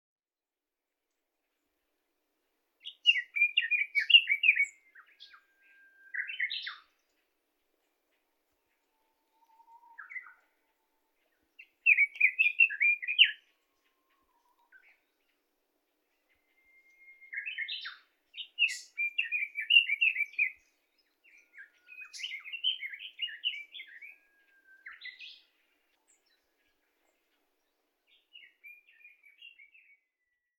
１　ソウシチョウ（相思鳥）スズメ目ソウシチョウ科ソウシチョウ属　大きさ：14～15㎝
ガビチョウの声と似ているが、ガビチョウほどの音量はない。また、クロツグミに似た声質とも言われている。
地鳴きは大きな声で「ジッ、ジッ、ジッ、ジリリリ」と聞こえる。
【録音②】　2024年5月　奈良県大和葛城山にて
30秒　さえずり・地鳴き